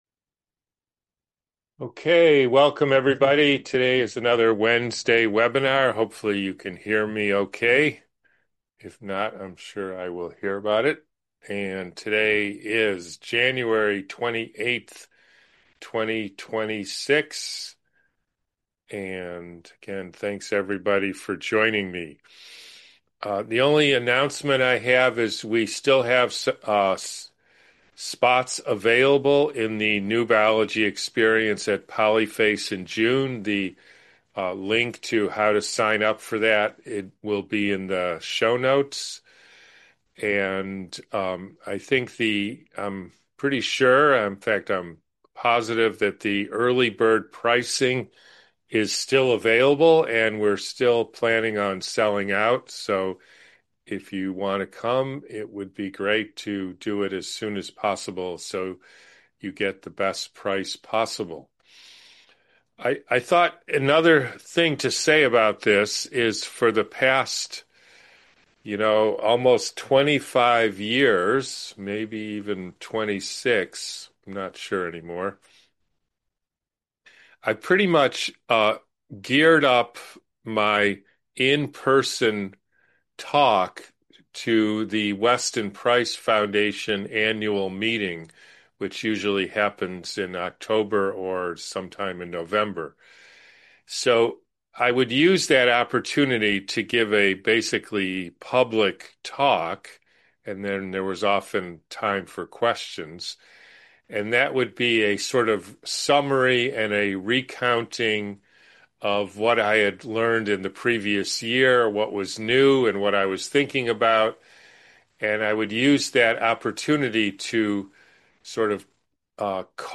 QA Webinar from January 28th 2026